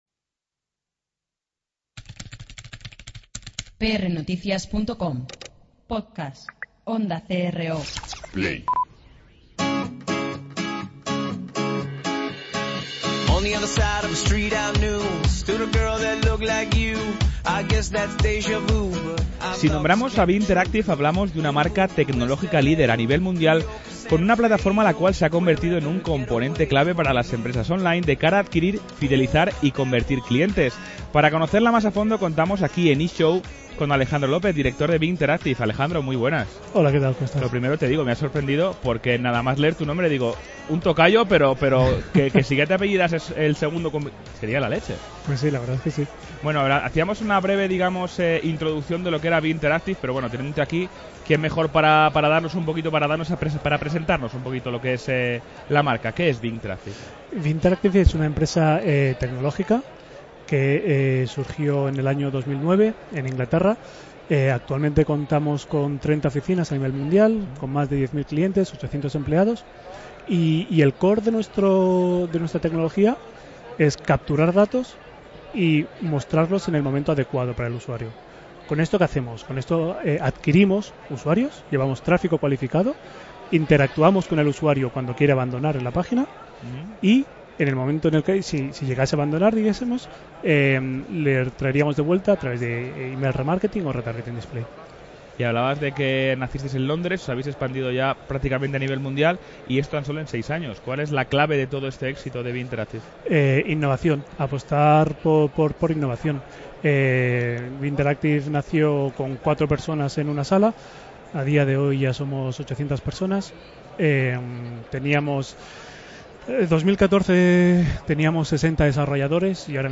en el stand de Mister Kiwi.